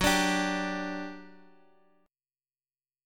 G5/F# chord {14 17 17 x x x} chord